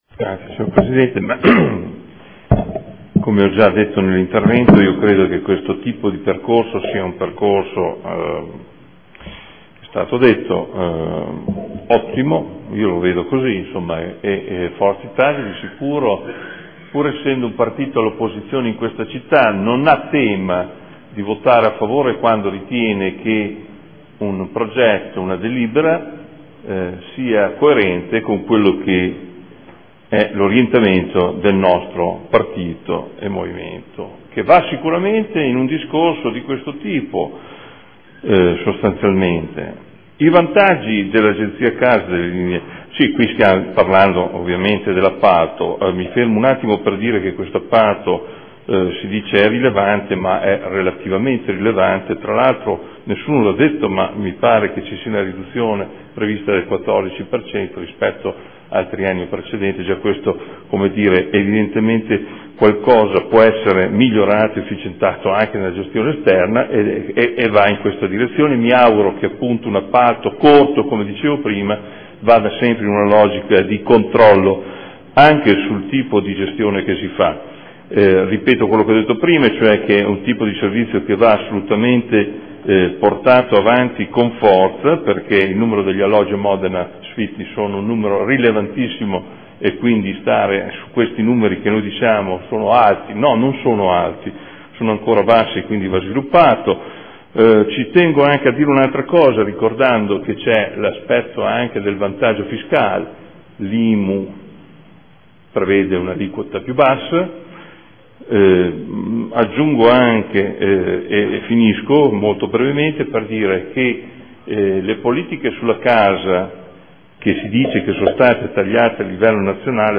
Adolfo Morandi — Sito Audio Consiglio Comunale
Seduta del 11/12/2014 Dichiarazione di voto. Linee guida per l’appalto per la gestione di attività e progetti orientati all’educazione all’abitare, mediazione condominiale e di vicinato – Periodo dall’1.4.2015 al 30.9.2016